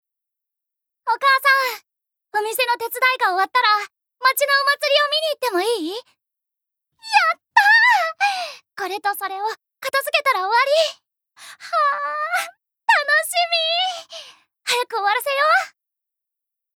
Voice Sample
セリフ３